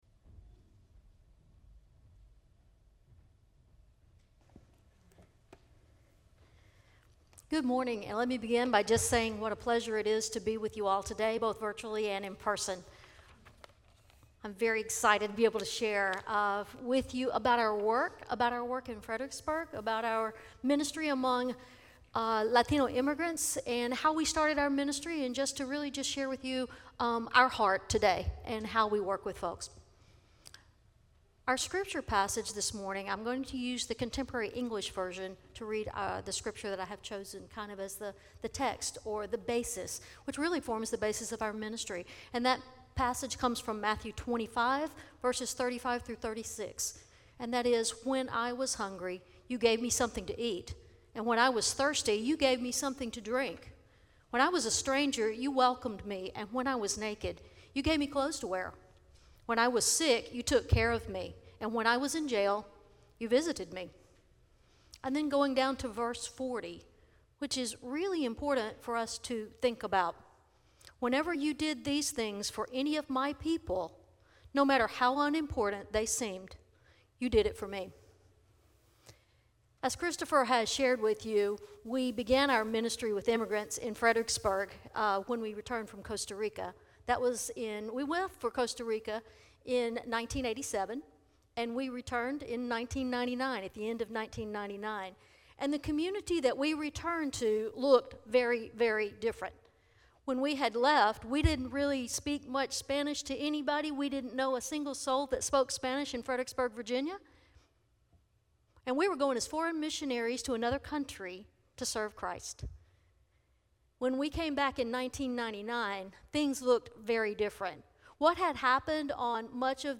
40 Service Type: Guest Preacher Bible Text